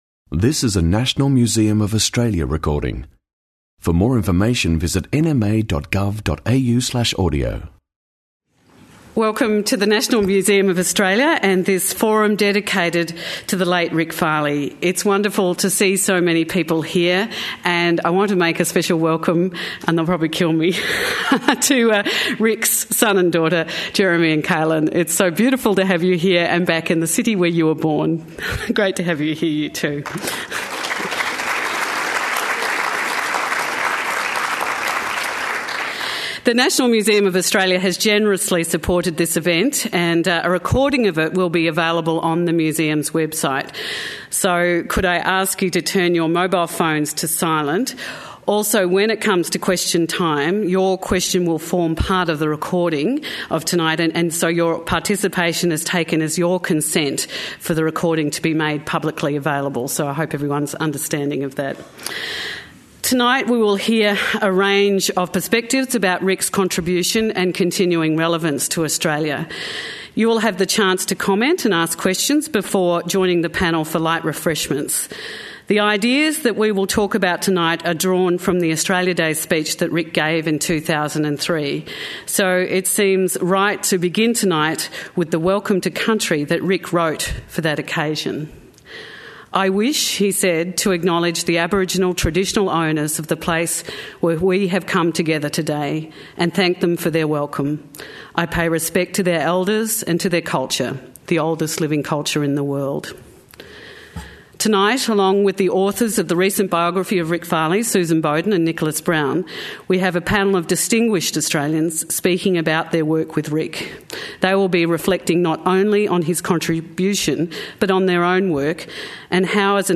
A way through: A forum for Rick Farley | National Museum of Australia